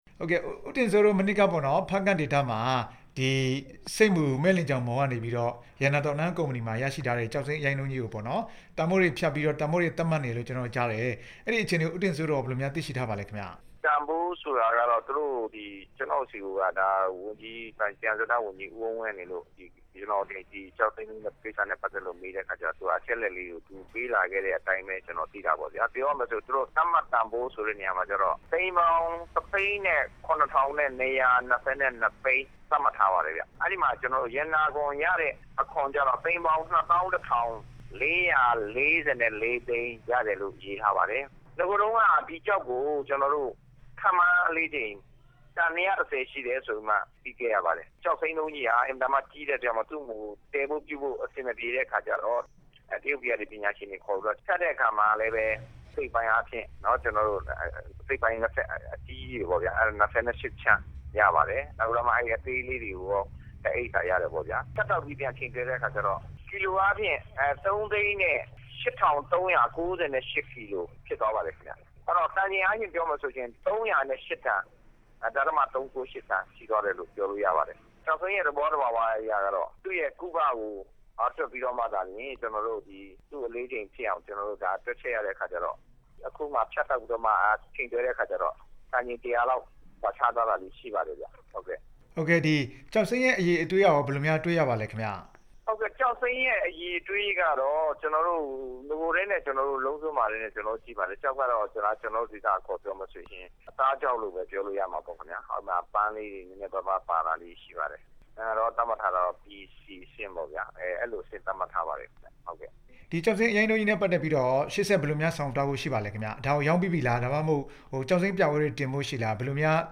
တန်ချိန် ၃၀၀ ကျော်ရှိတဲ့ ကျောက်စိမ်းအရိုင်းတုံး ပြသရောင်းချမယ့်အကြောင်း မေးမြန်းချက်